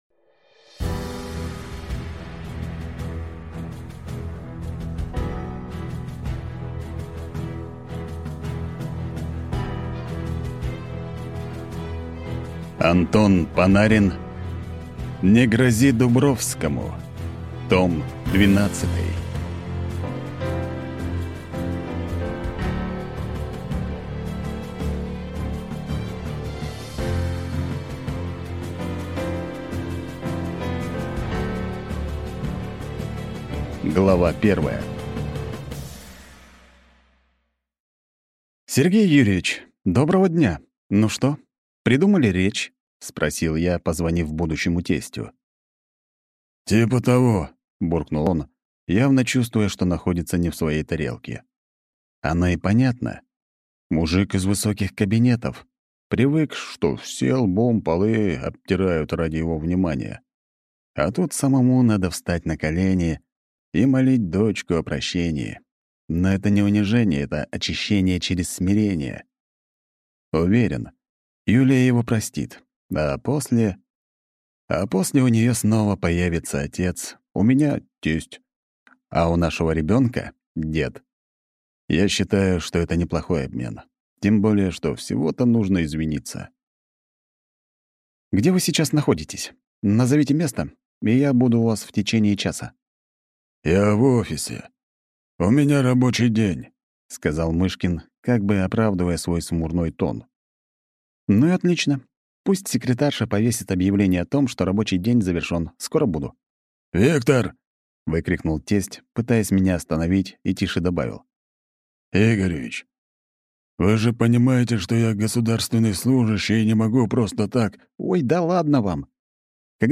Аудиокнига «Не грози Дубровскому! Том 12».